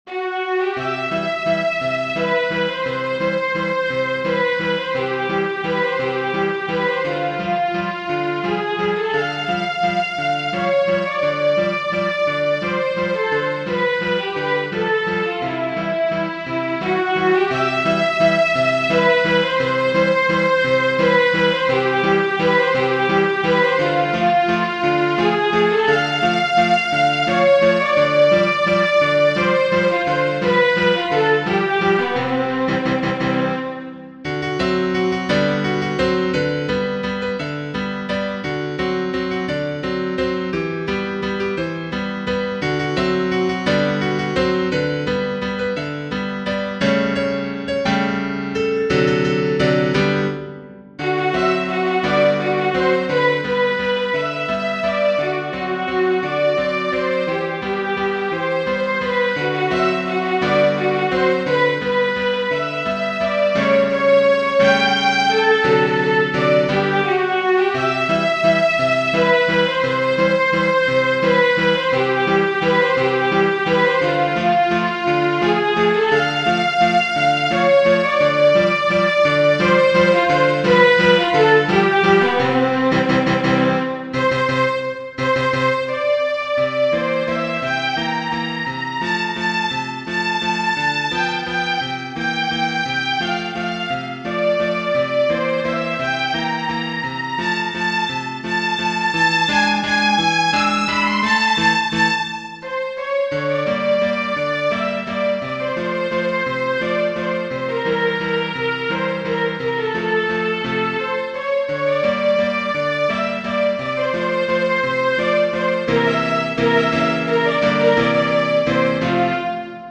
Strauss, J. Jr. Genere: Ballabili Wiener Blut (Sangue viennese) op. 354, è un valzer di Johann Strauss (figlio).